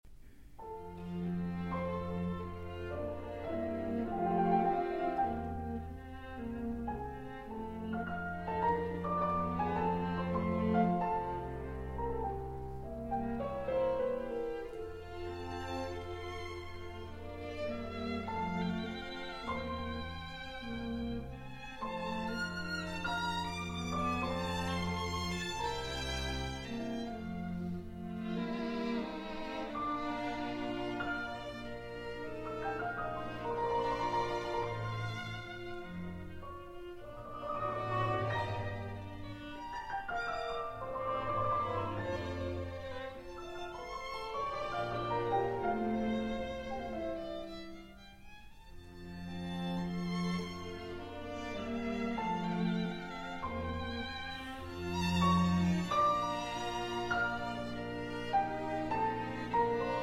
performed live
Piano